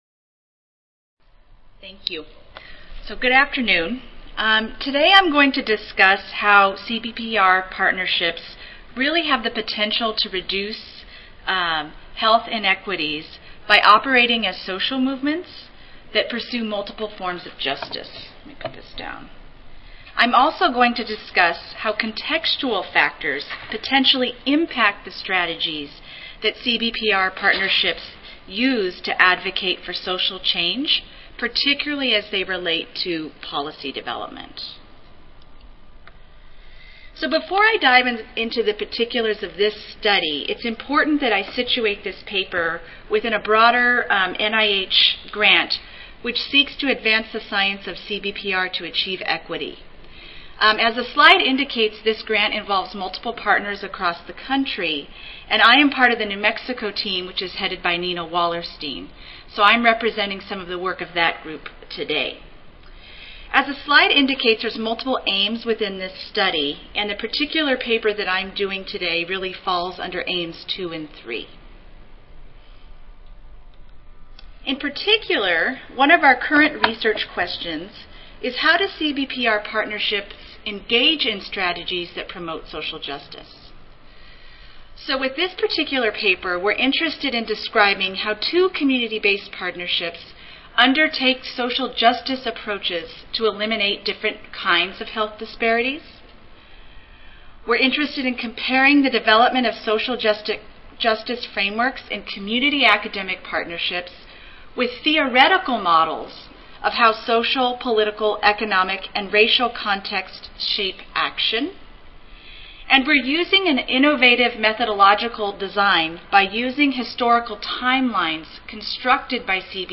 In this session panelists will describe research activities that aim to inform policy and enhance the capacity of communities to participate in, evaluate and monitor research.